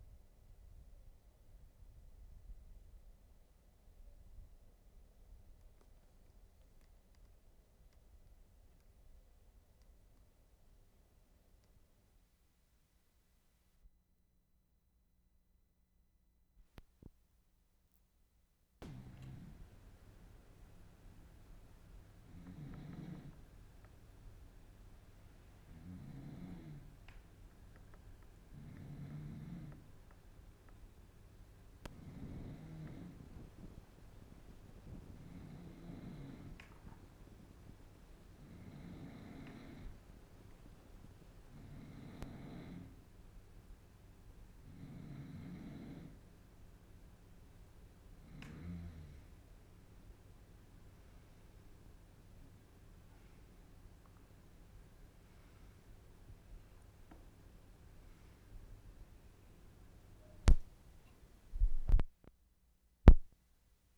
WORLD SOUNDSCAPE PROJECT TAPE LIBRARY
GUEST SNORING IN SKRUV HOTEL
2. Has to be turned up in volume to be audible.